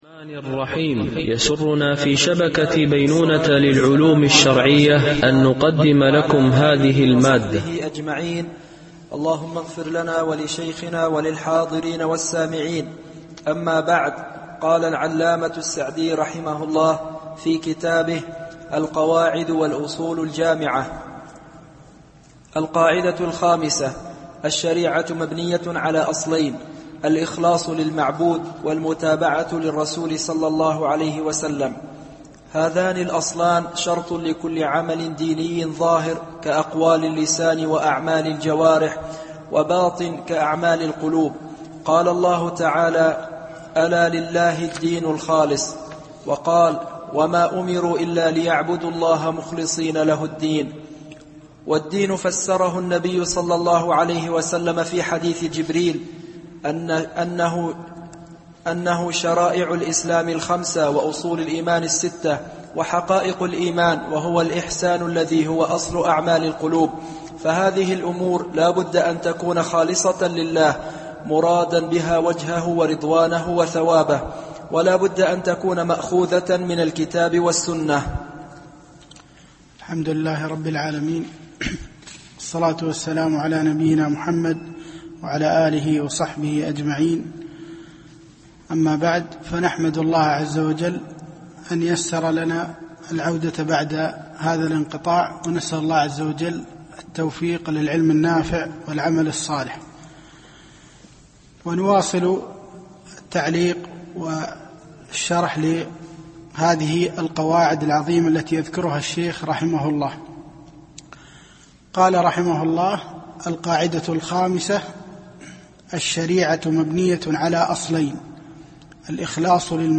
الدرس 5